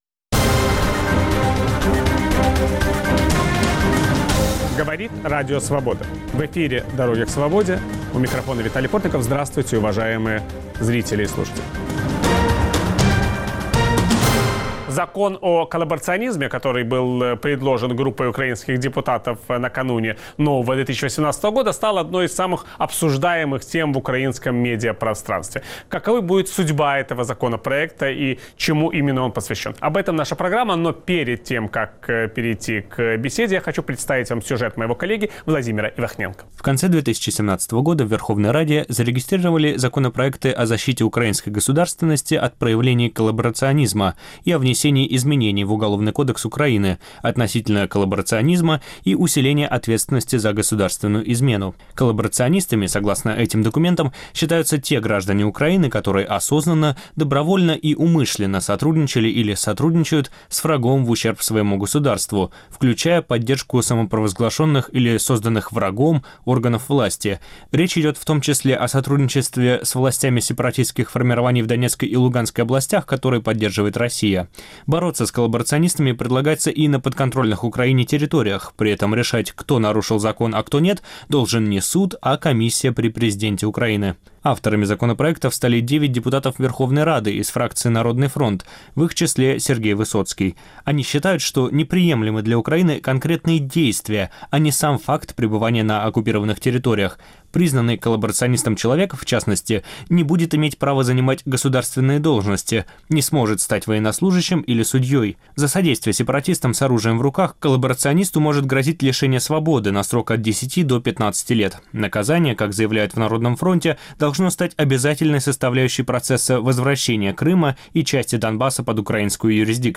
В киевской студии Радио Свобода обсуждаем Украину после Майдана. Удастся ли украинцам построить демократическое европейское государство?
Все эти и многие другие вопросы обсуждаем с политиками, журналистами и экспертами.